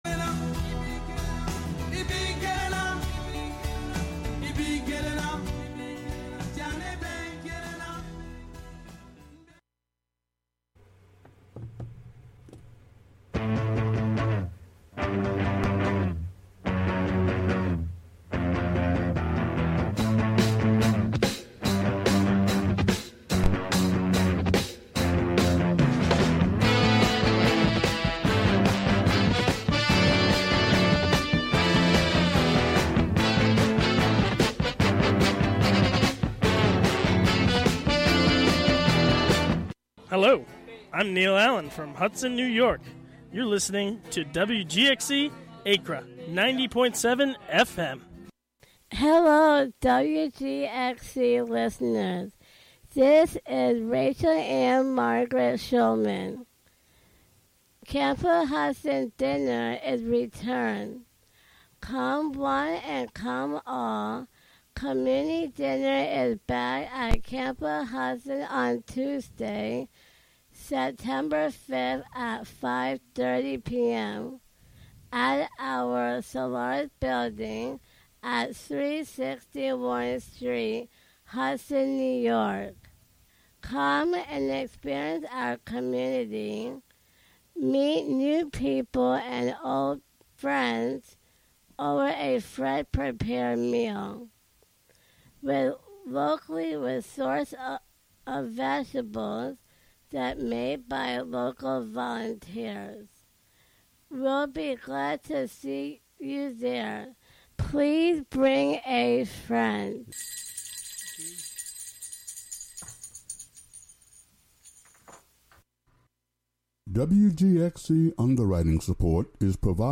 An hour of bubbling hot funk